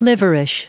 Prononciation du mot liverish en anglais (fichier audio)
Prononciation du mot : liverish